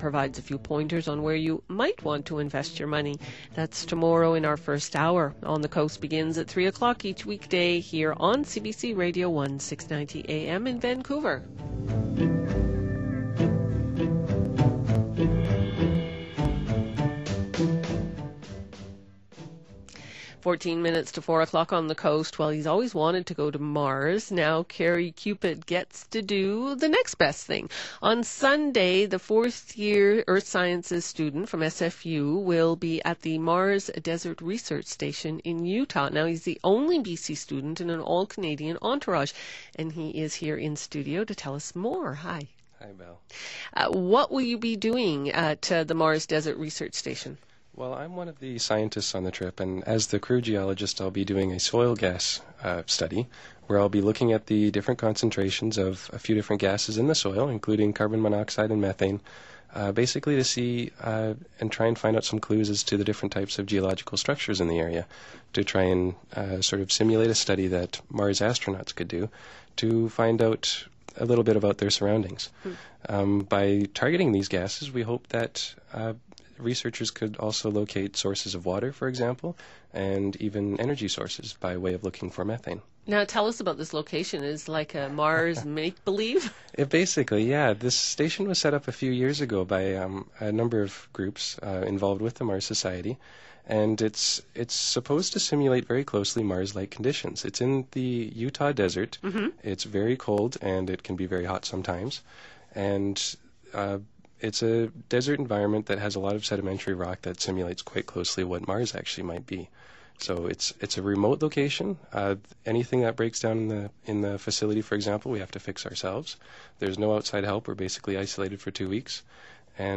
On Orbit » Blog Archive » CBC Radio interview